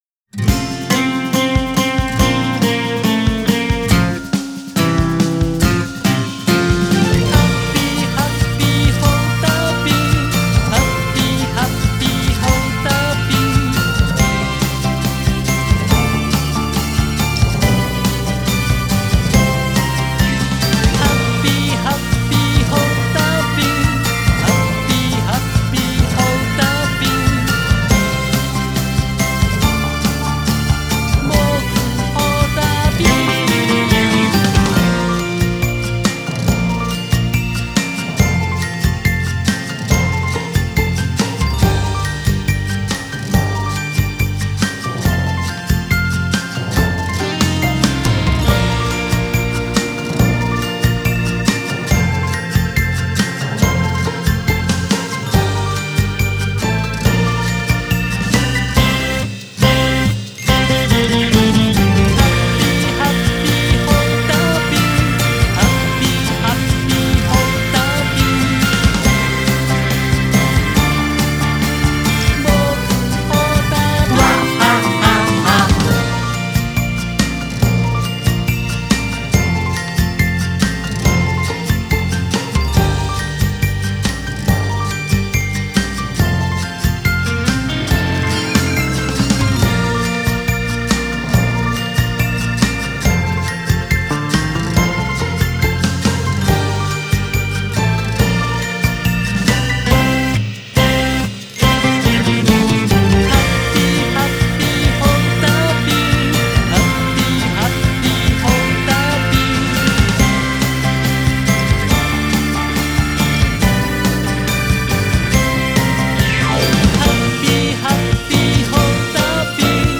口ずさみやすい曲調で、元気いっぱい、楽しく踊っています。